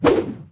footswing3.ogg